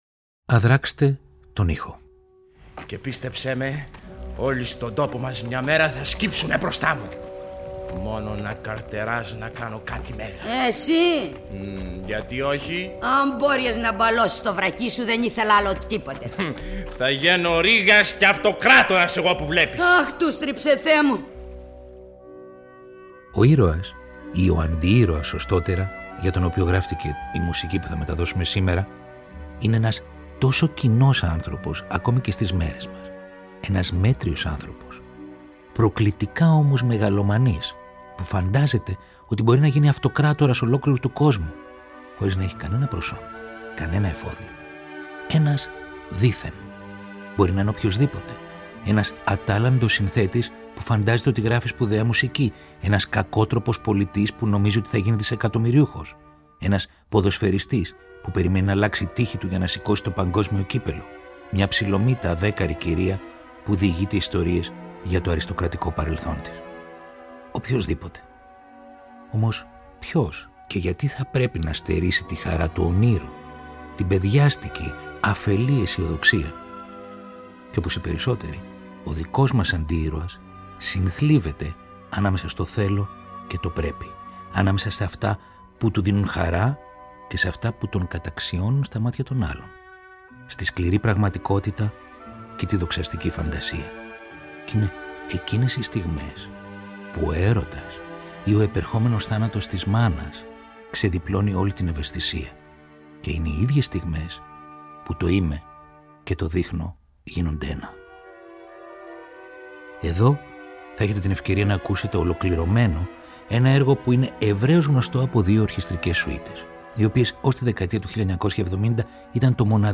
Ένας αντιήρωας που λοιδορείται και βρίσκει διέξοδο στη φαντασία και το όνειρο. Ολόκληρη η εκπληκτική σκηνική μουσική του Έντβαρντ Γκριγκ για το αριστουργηματικό έργο του Ίψεν Peer Gynt, μαζί με αποσπάσματα από την ηχογράφηση του 1960 της ραδιοφωνικής μεταφοράς, με πρωταγωνιστή τον Δημήτρη Παπαμιχαήλ.